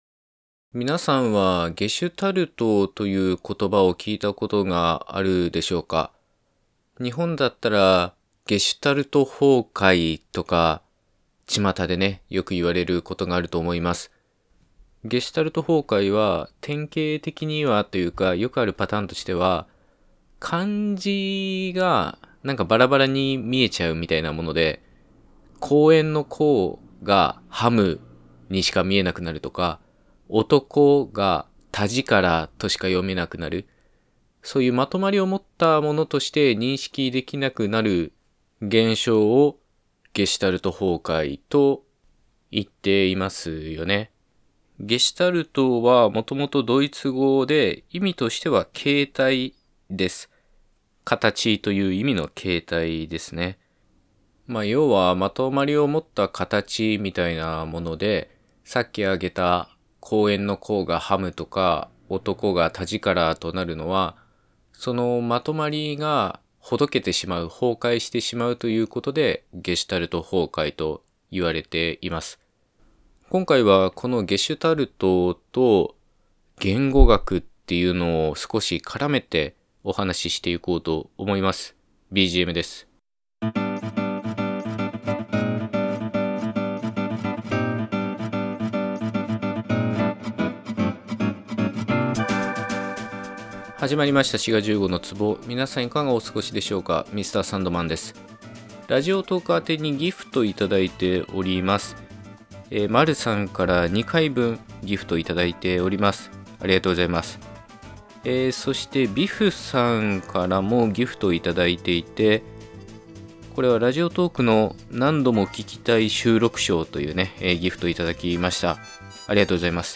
・ポッドキャストアワード2020推薦作品 ・Spotify公式プレイリスト選出 10分ほどの言語学トークを毎週火・土配信！